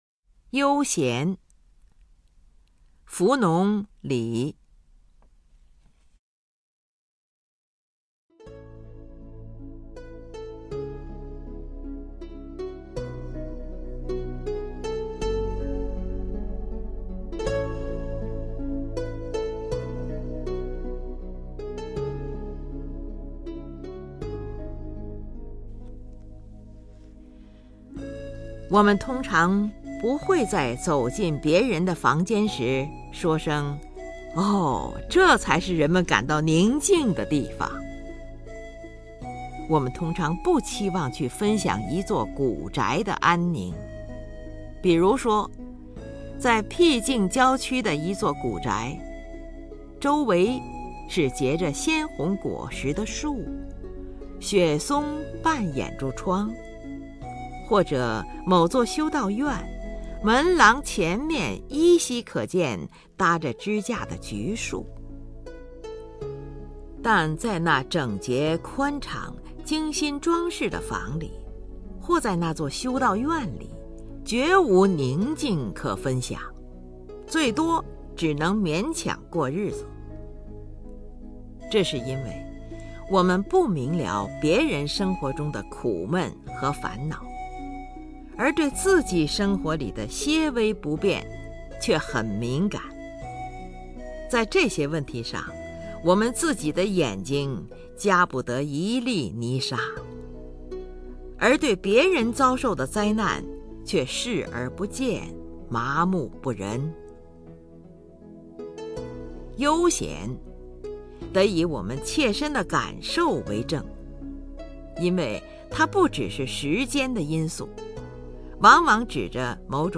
首页 视听 名家朗诵欣赏 林如
林如朗诵：《悠闲》(（英）浮龙·李)